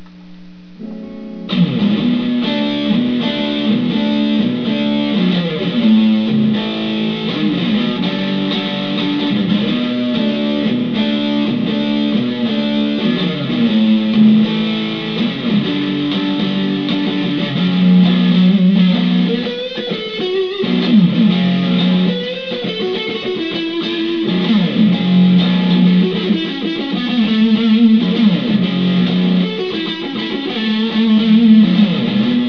These riffs were recorded in mono to minimize file size.